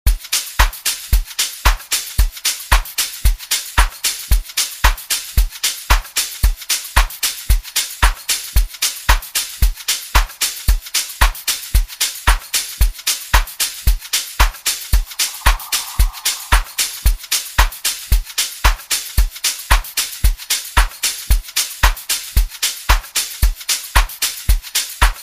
elements of piano, jazz, and deep house